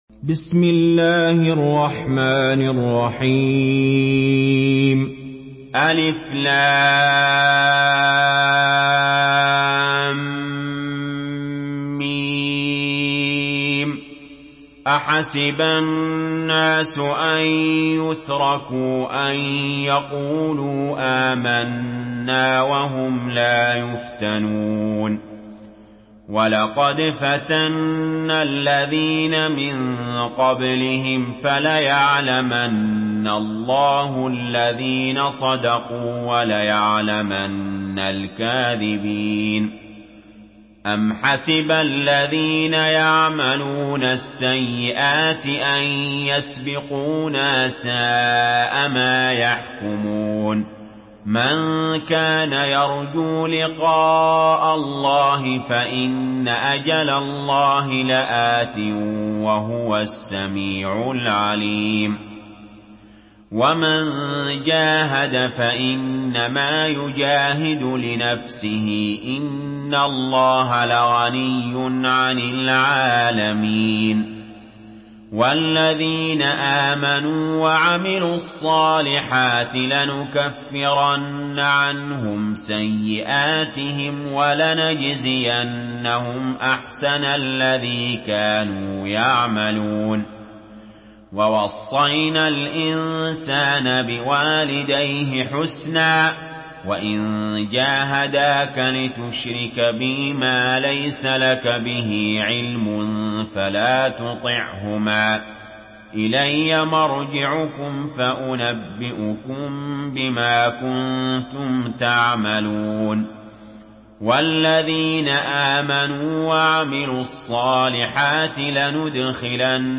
Surah Al-Ankabut MP3 by Ali Jaber in Hafs An Asim narration.
Murattal Hafs An Asim